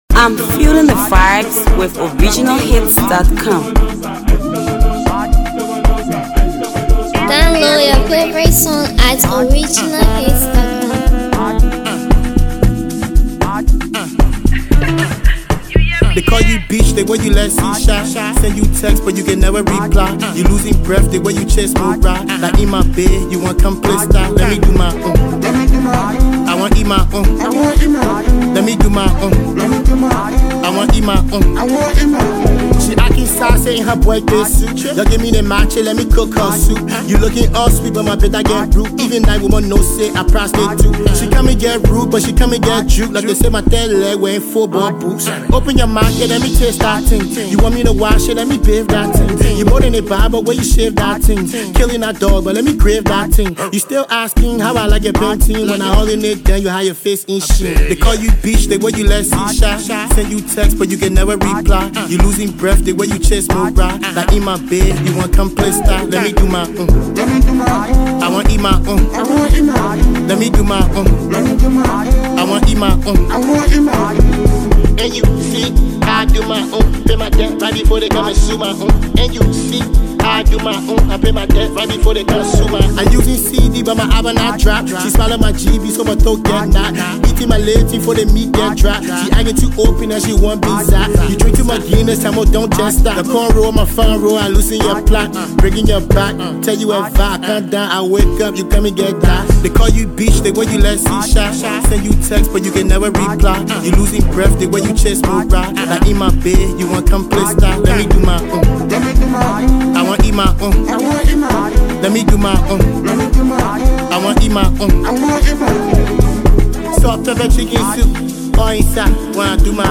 Grab your copy of this jam from the topnotch trap rap guru.